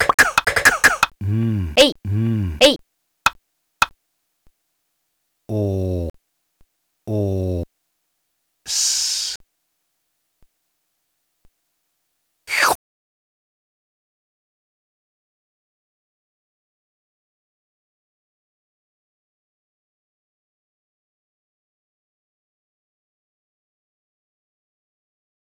einprägsames spiel mit dem perkussivem klanganteilen des wort/sprachmaterials. gut gesetzter schlusseffekt.